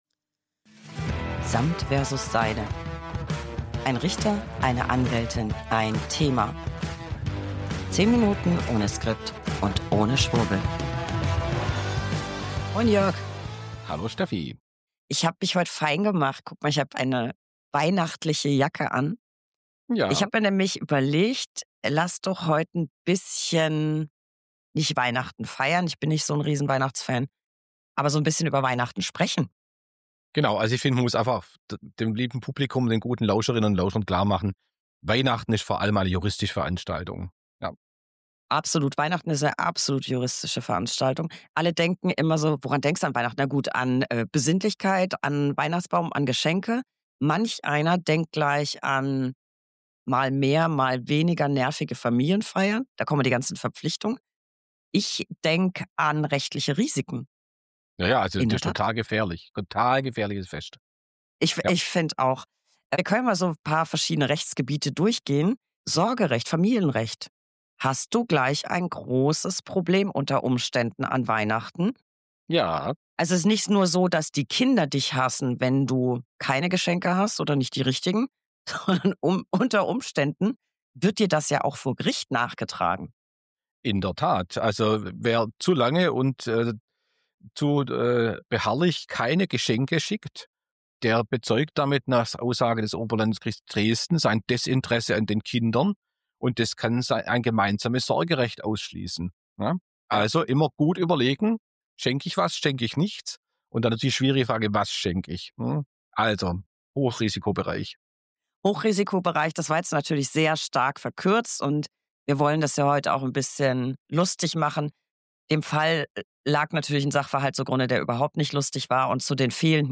1 Anwältin + 1 Richter + 1 Thema. 10 Minuten ohne Skript und ohne Schwurbel. Ach was solls: 40 weihnachtliche Minuten ohne Skript und ohne Schwurbel *unbeauftragte Werbung* Advent, Advent, die Bude brennt.